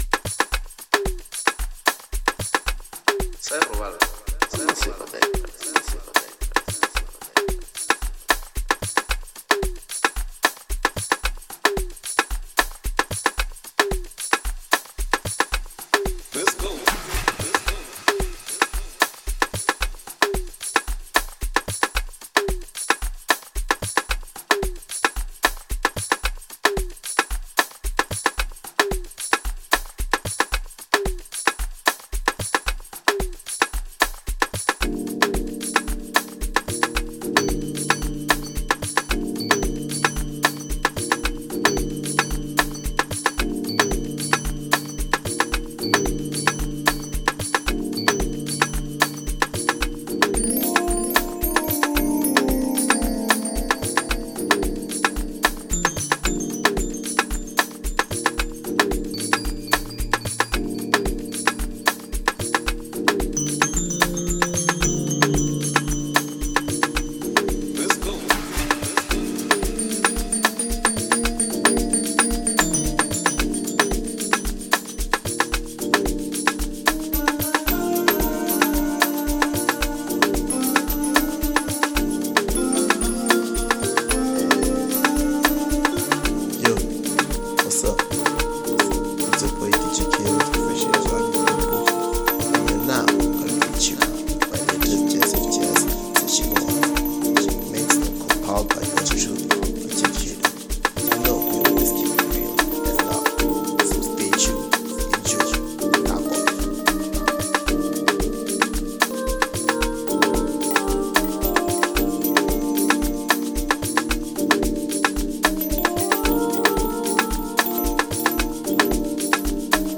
07:26 Genre : Amapiano Size